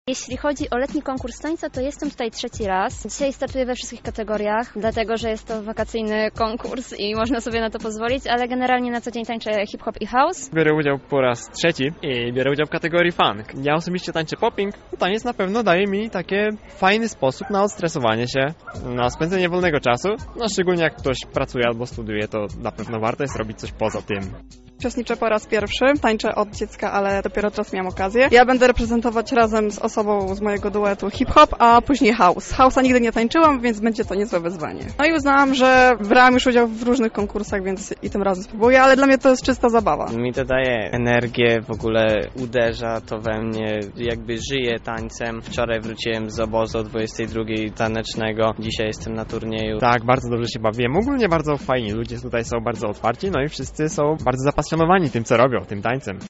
To już szósta edycja wydarzenia, które odbyło się w ramach projektu Centralny Plac Zabaw w Wirydarzu Centrum Kultury.
Zapytaliśmy ich o osobiste doświadczenia związane z tą sztuką